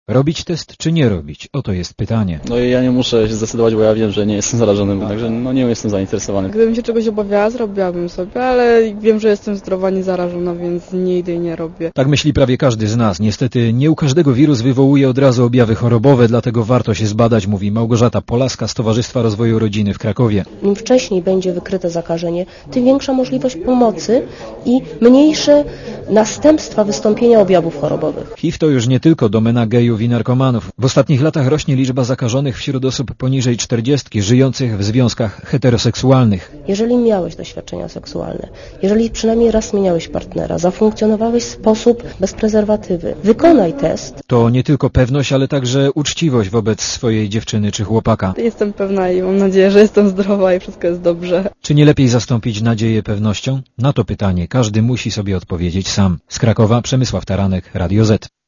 Komentarz audio (227kB) Światowy Dzień AIDS od 1988 r. z inicjatywy Światowej Organizacji Zdrowia (WHO) jest obchodzony 1 grudnia.